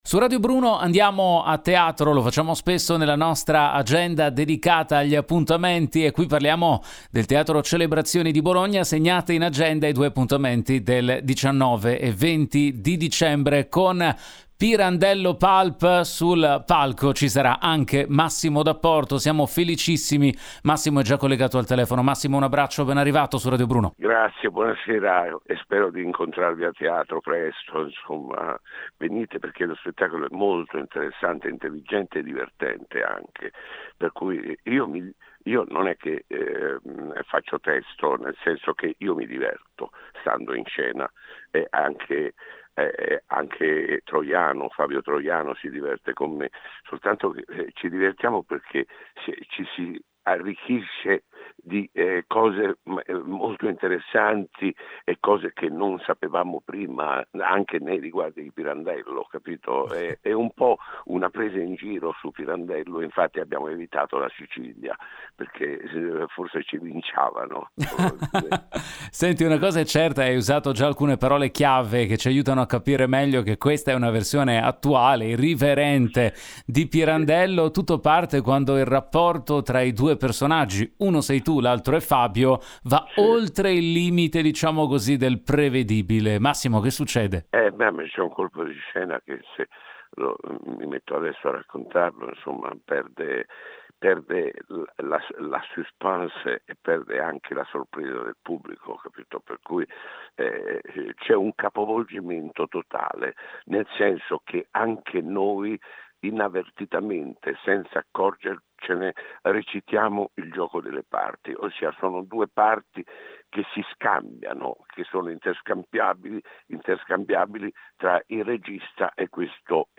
Home Magazine Interviste “Pirandello Pulp” al Teatro Celebrazioni di Bologna